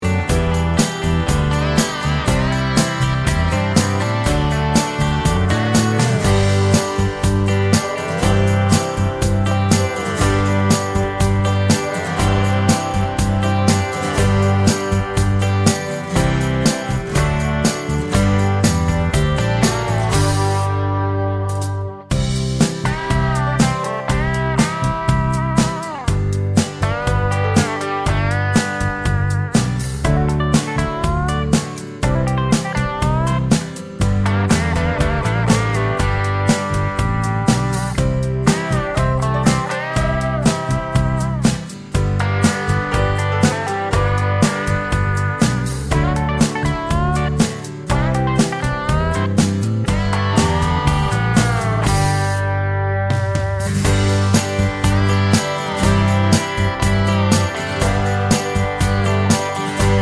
no Backing Vocals Country (Male) 2:58 Buy £1.50